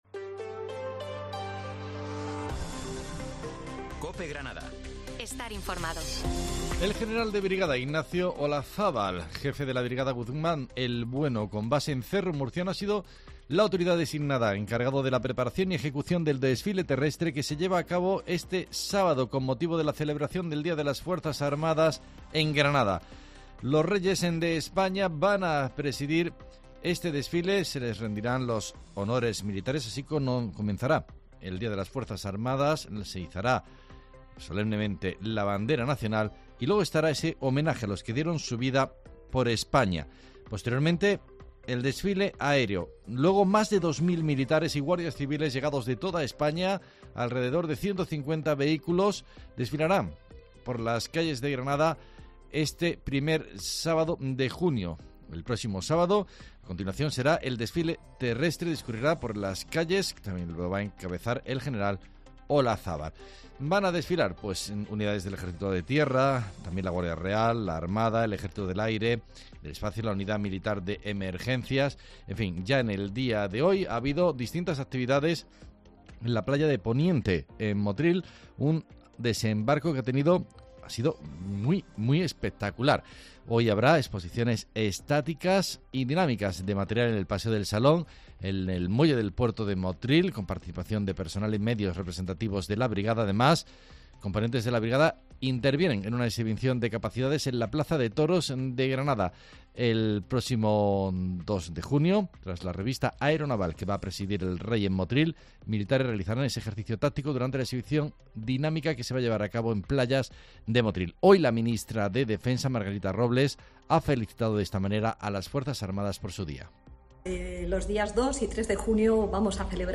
Mediodía en Granada, Informativo del 30 de mayo